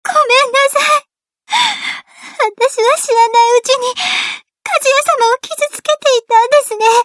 サンプルボイスは各キャラクターの下にあります